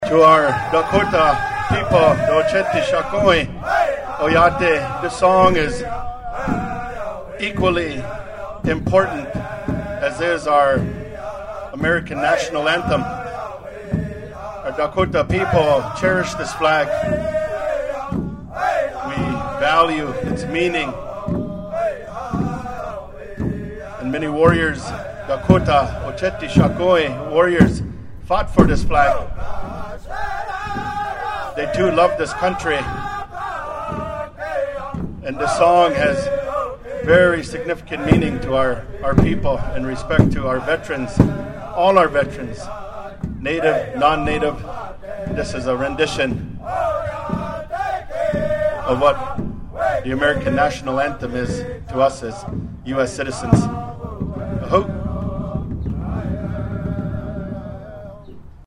Singing, dancing, praying, praising and protesting all took place on the lawn of the South Dakota State Capitol in Pierre today (May 20) during the Governor’s Round Dance event.
While drummers from the Standing Rock and Crow Creek Sioux Tribes sang, state Secretary of Tribal Relations David Flute interpreted their Flag Song.